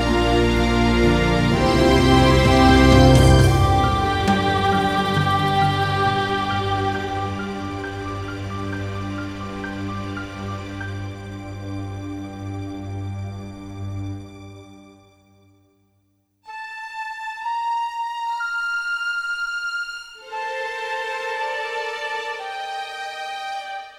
no Backing Vocals Soundtracks 3:53 Buy £1.50